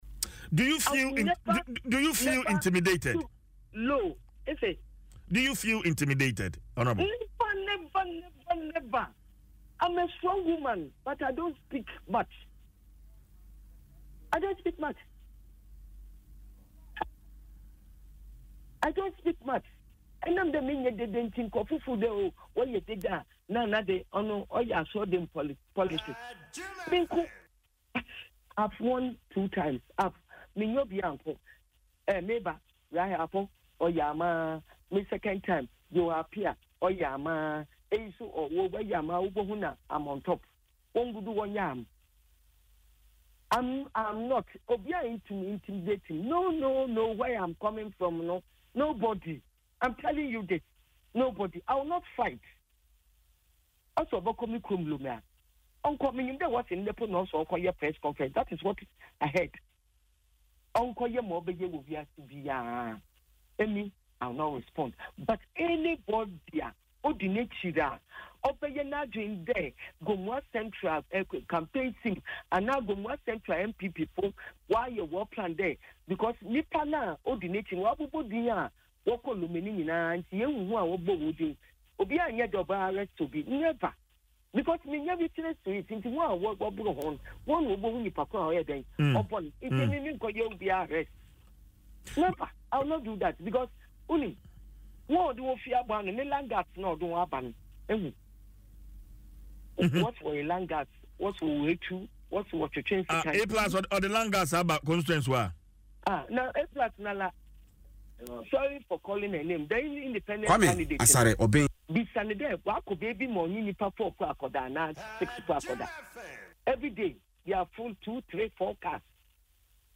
In an interview on Adom FM Dwaso Nsem, the two-time MP noted that she is a peaceful and quiet person.
Listen to the MP in the audio above: